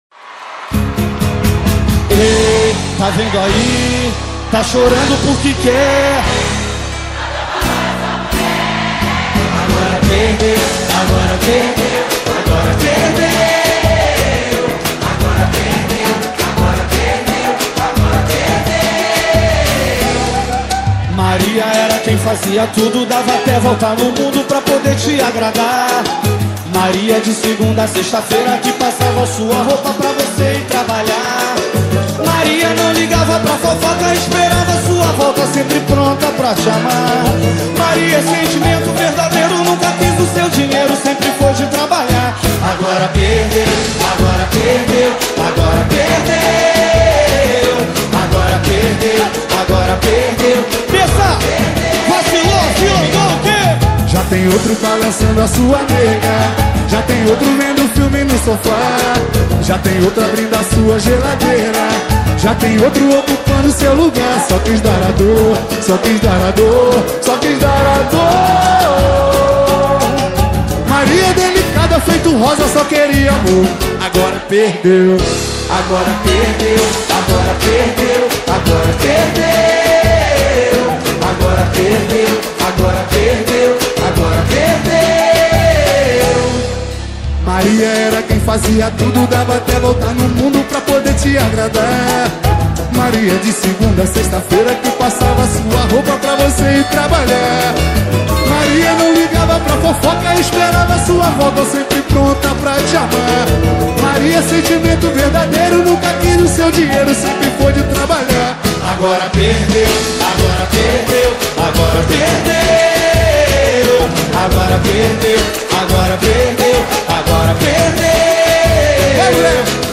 2024-10-09 13:42:11 Gênero: Pagode Views